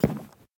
Minecraft Version Minecraft Version latest Latest Release | Latest Snapshot latest / assets / minecraft / sounds / block / hanging_sign / step3.ogg Compare With Compare With Latest Release | Latest Snapshot